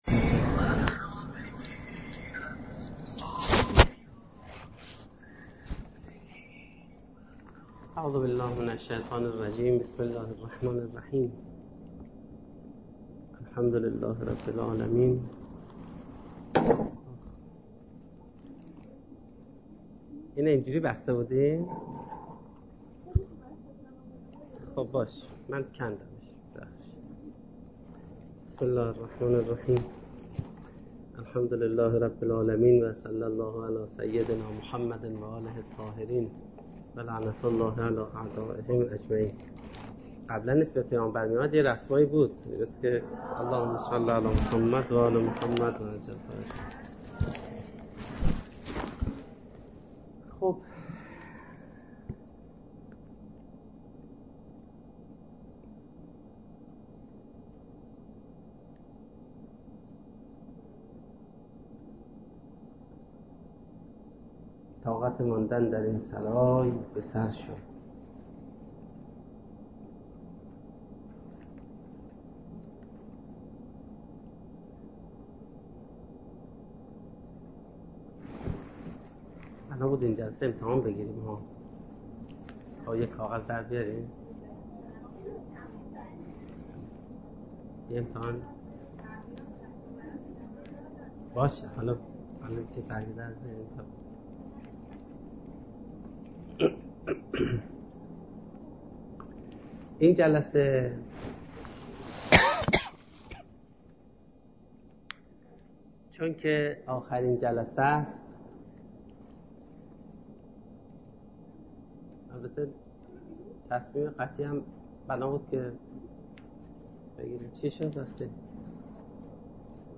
سخنرانی
در دانشگاه فردوسی